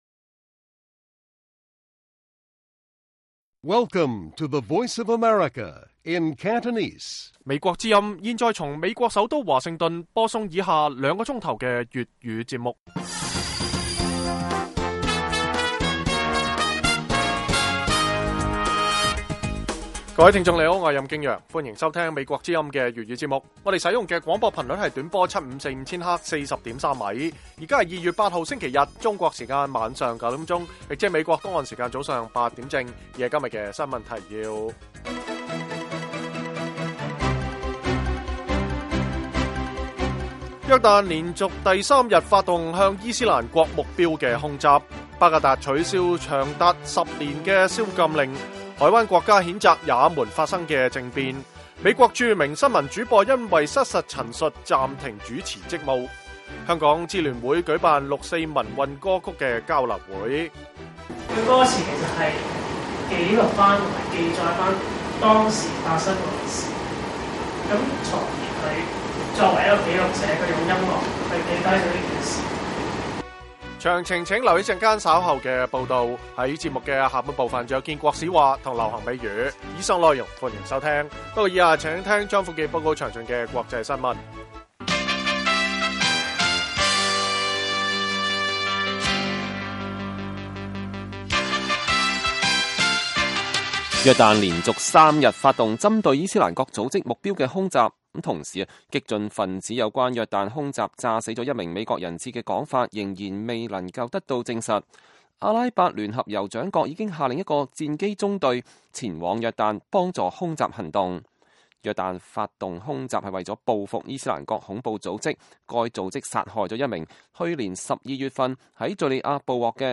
北京時間每晚9－10點 (1300-1400 UTC)粵語廣播節目。內容包括國際新聞、時事經緯和英語教學。